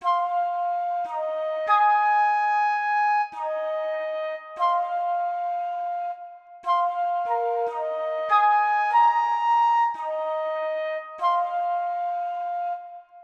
Restless_Flute.wav